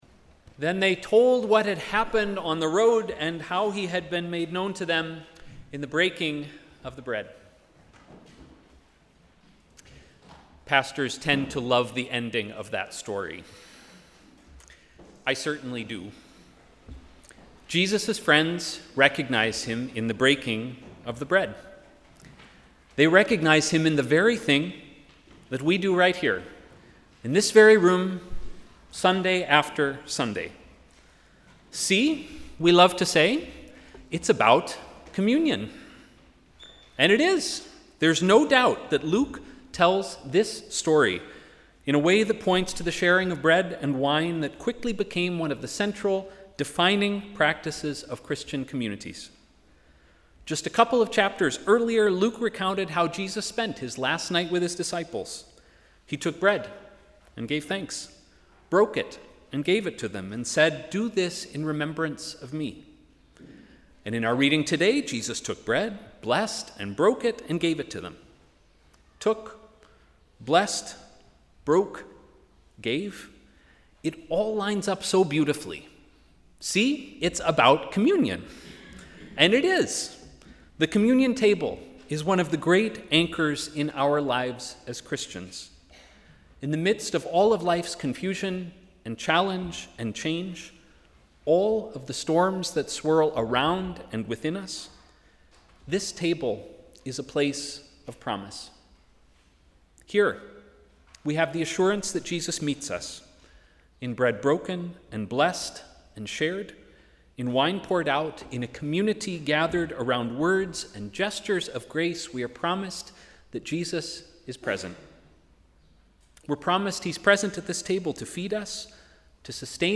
Sermon: ‘As a stranger’